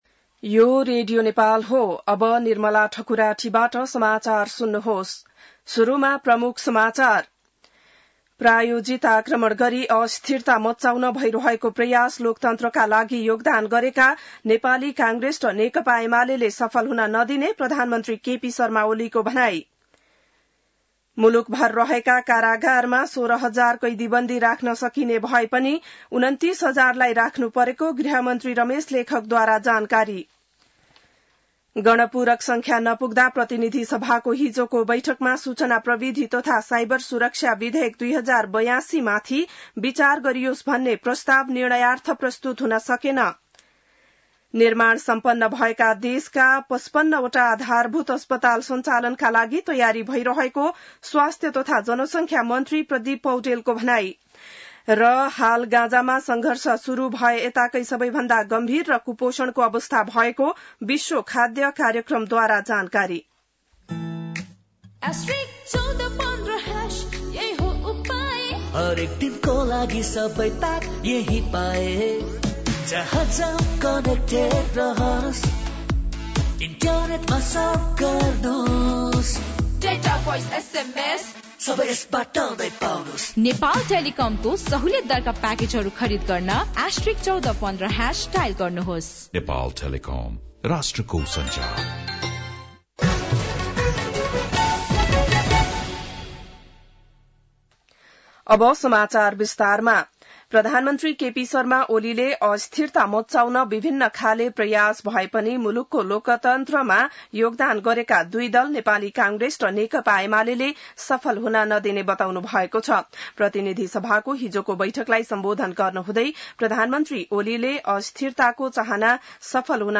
बिहान ७ बजेको नेपाली समाचार : २९ साउन , २०८२